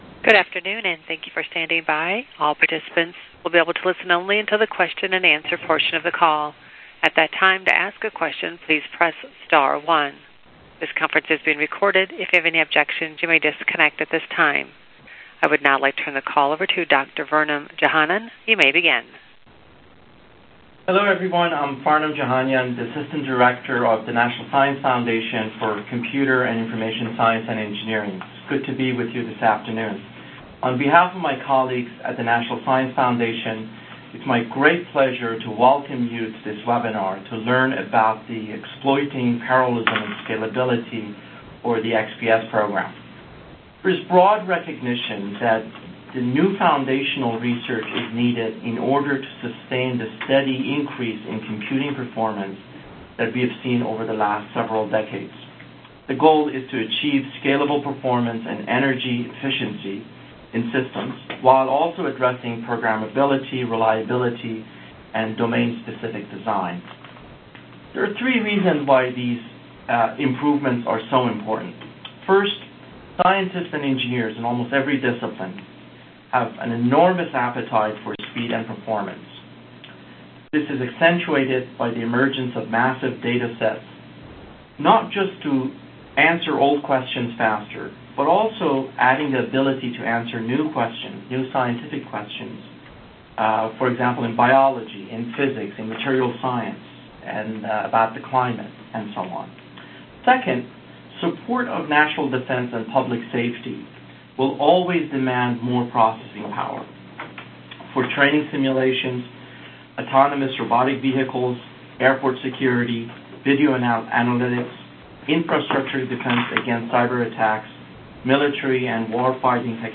Exploiting Parallelism and Scalability (XPS) program Webinar